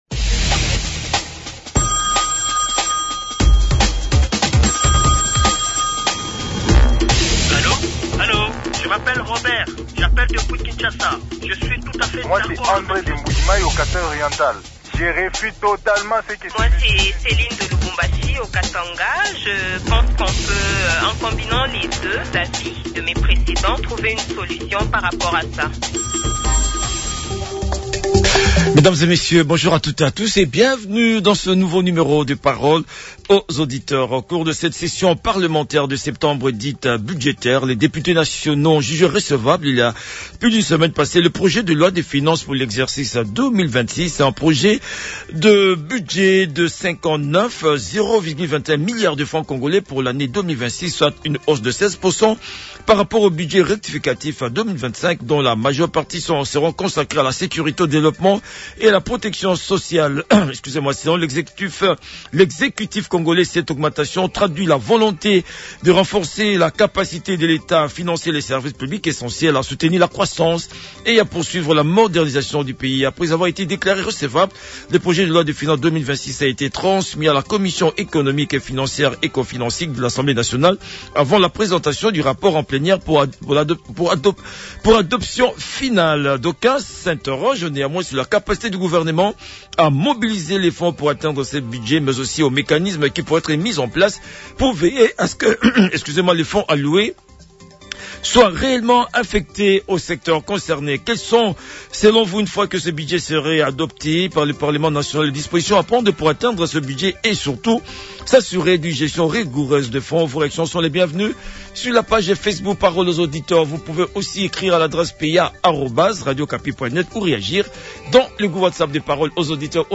Les auditeurs ont échangé avec l'honorable Jethro Muyombi, député national et président de la sous-commission des affaires étrangères, coopération internationale et francophonie à l'Assemblée nationale.